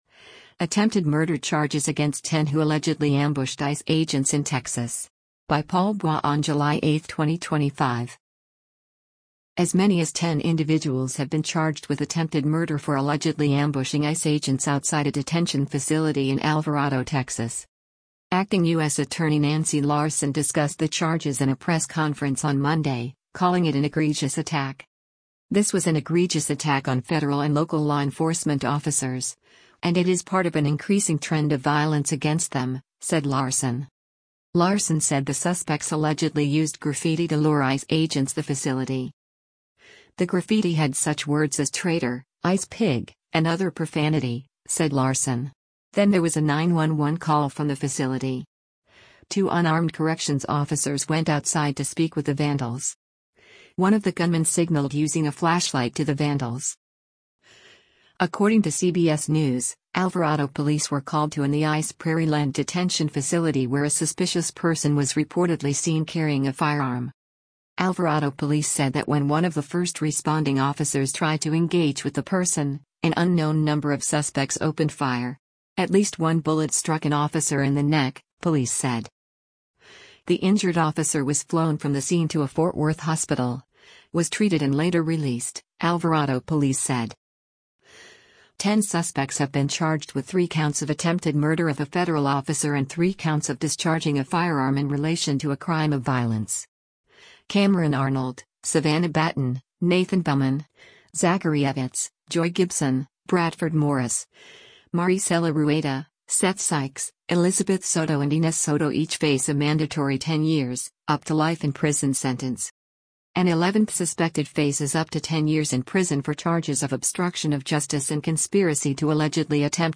Acting U.S. Attorney Nancy Larson discussed the charges in a press conference on Monday, calling it an “egregious attack.”